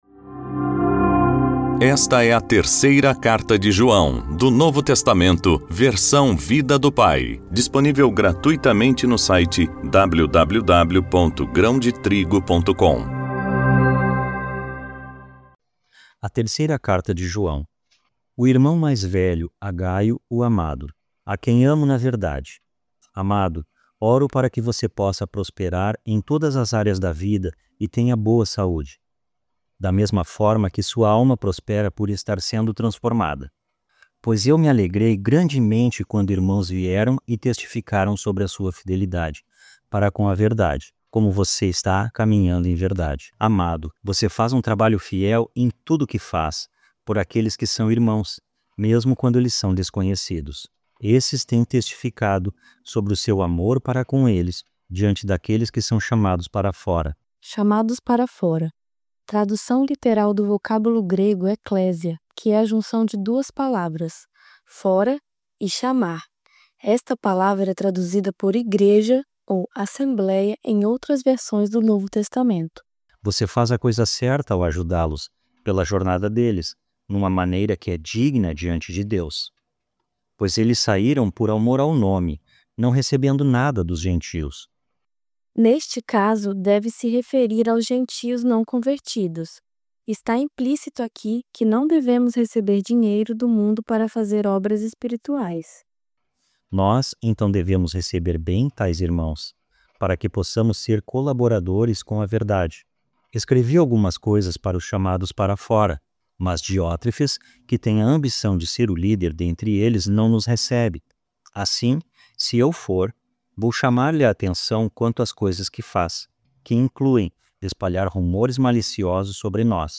voz-audiobook-novo-testamento-vida-do-pai-terceira-joao-capitulo-1.mp3